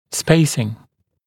[speɪsɪŋ][‘спэйсин]тремы, промежутки между зубами